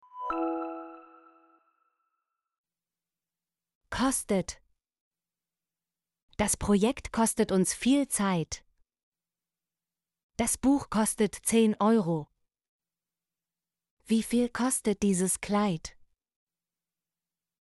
kostet - Example Sentences & Pronunciation, German Frequency List